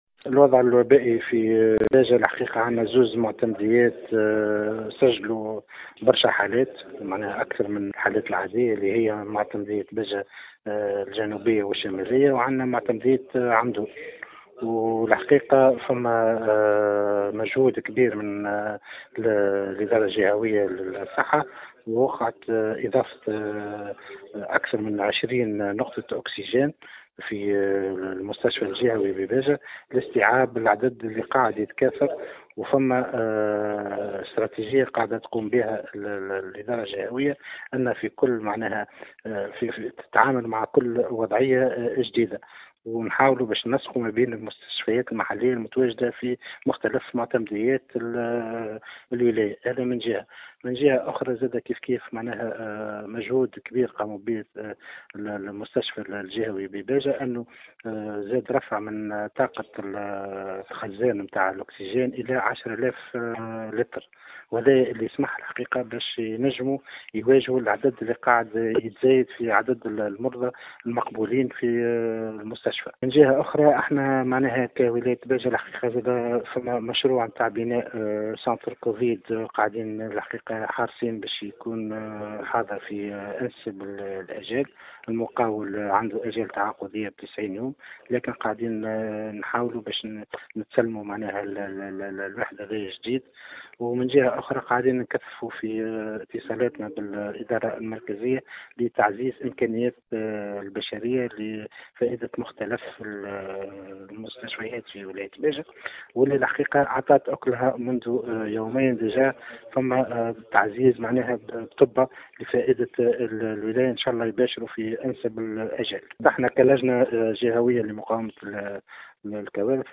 وشدّد الوالي في تصريح للجوهرة أف أم، حرص السلطات الجهوية على تعزيز الإمكانيات البشرية من الإطارات الطبية إضافة إلى التسريع في إحداث مشروع مركز كوفيد-19 في أقرب الآجال، خاصة وأنّ الآجال التعاقدية مع المقاول حُدّدت ب90 يوما.